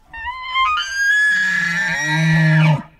Марал вопит